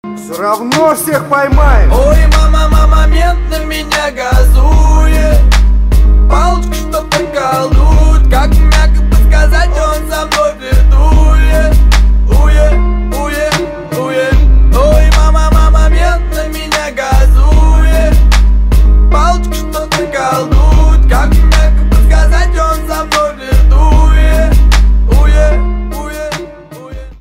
• Качество: 192, Stereo
русский рэп